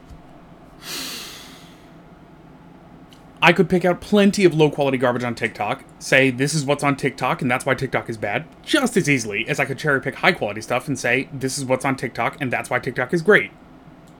up to line 12 - but it can hear the AC